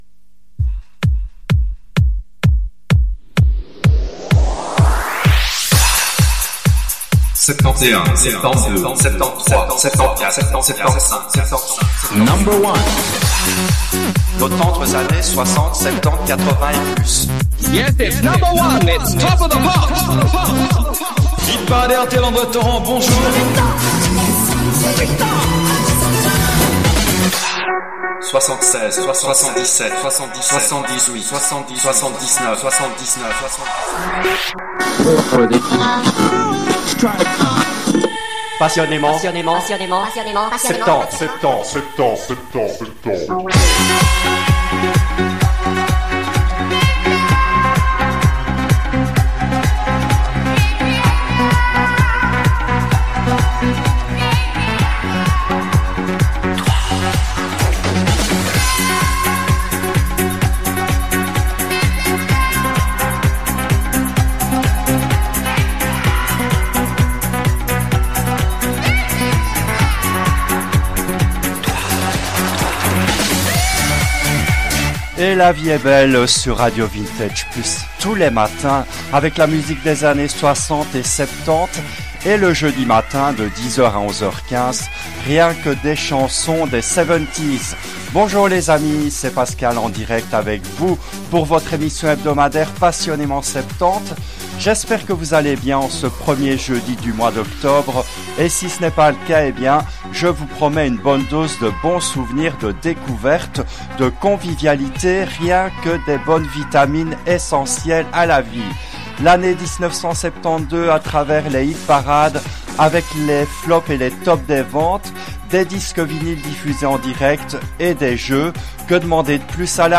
Cette émission a été diffusée en direct le jeudi 12 octobre 2023 à 10h depuis les studios belges de RADIO RV+.